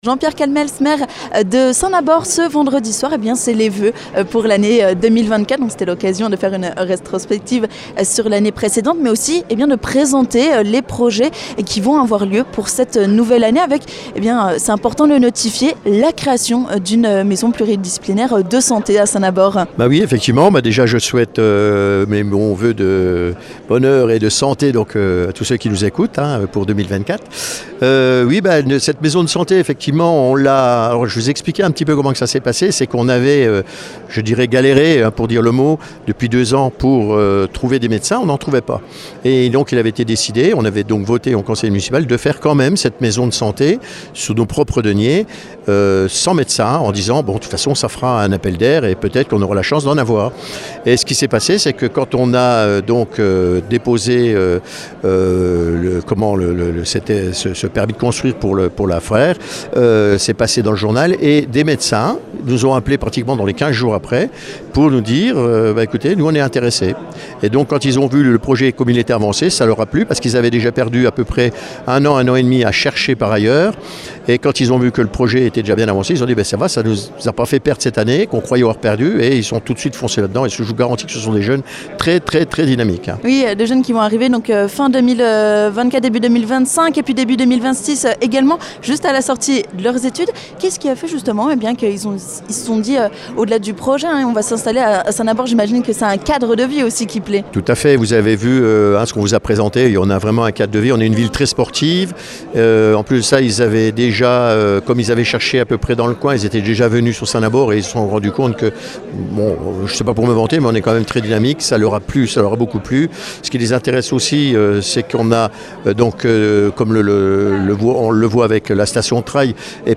On fait le point sur les projets de l'année 2024 avec le maire de Saint-Nabord, Jean-Pierre Calmels.